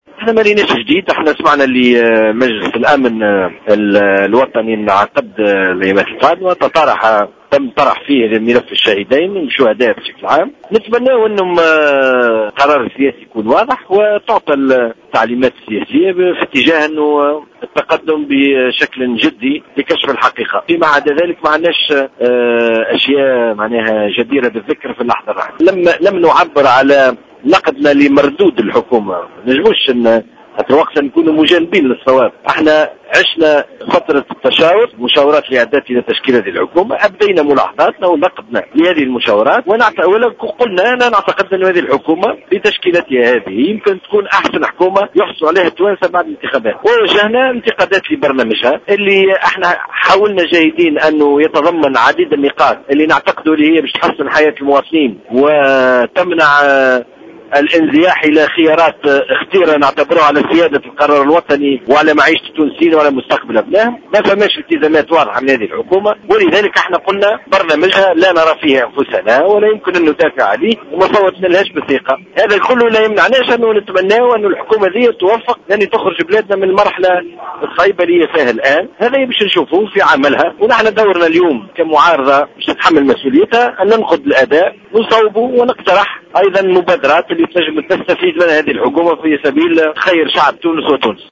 Le secrétaire général du parti des patriotes démocrates unifié, Zied Lakhdar, a rappelé, vendredi au micro de Jawhara, que la position politique du Front Populaire a été claire dès le début par rapport au gouvernement Habib Essid et de son programme.